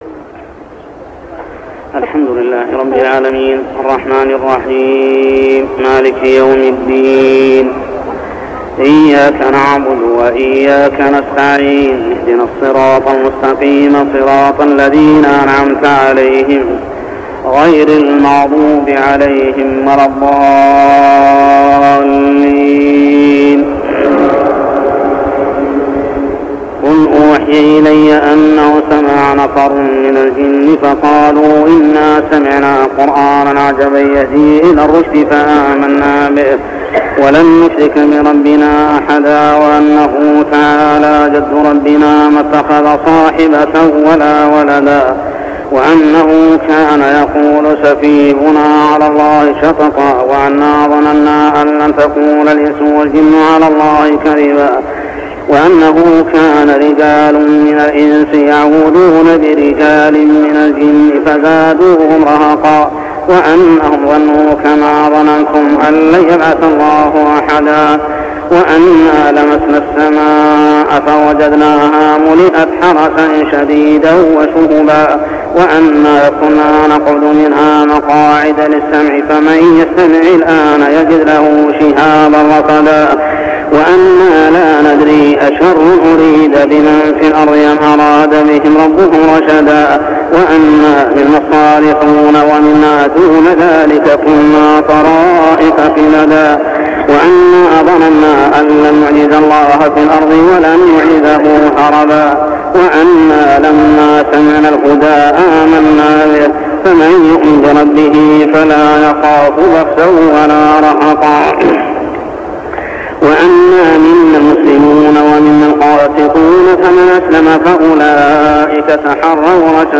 صلاة التراويح عام 1399هـ من سورة الجن كاملة حتى سورة الطارق كاملة | Tarawih prayer from Surah Al-jinn to surah Al-tairq > تراويح الحرم المكي عام 1399 🕋 > التراويح - تلاوات الحرمين